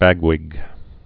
(băgwĭg)